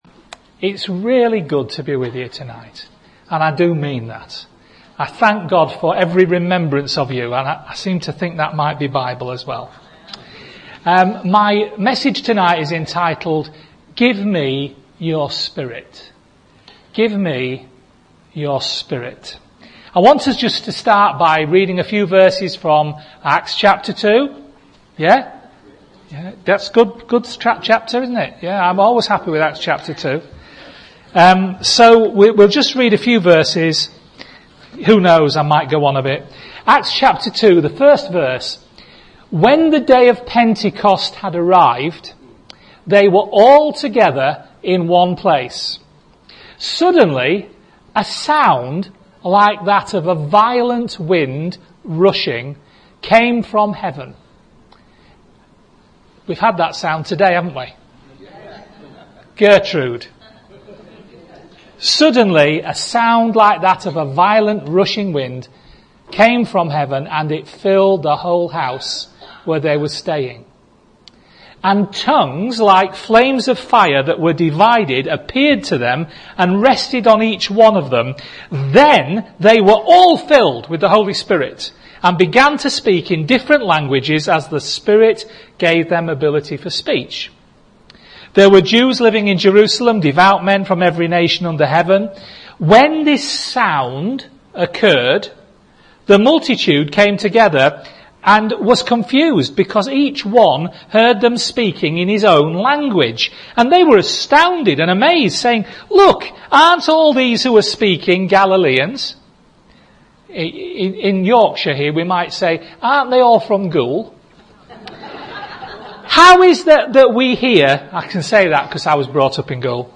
Give Me Your Spirit (Hollybush)